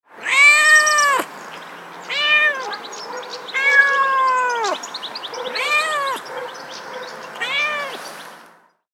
دانلود صدای میو میو گربه در خیابان و در یک روز گرم تابستانی از ساعد نیوز با لینک مستقیم و کیفیت بالا
جلوه های صوتی